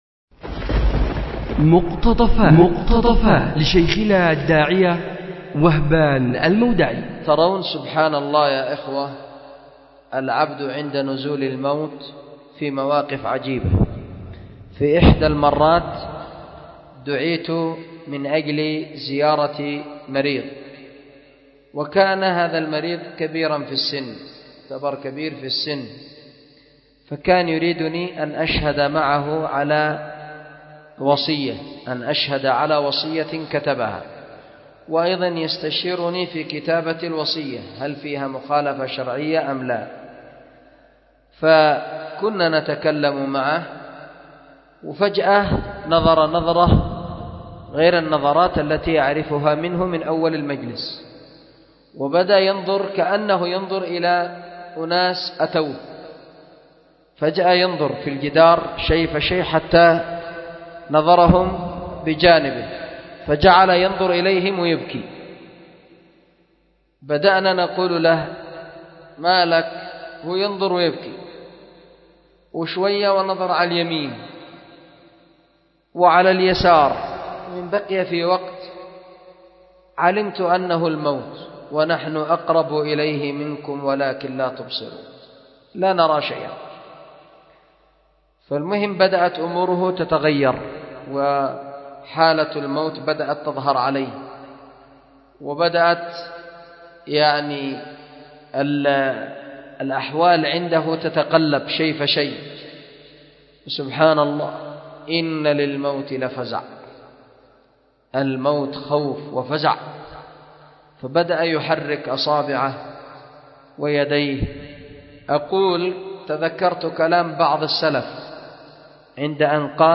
أُلقي بدار الحديث للعلوم الشرعية بمسجد ذي النورين ـ اليمن ـ ذمار 1444هـ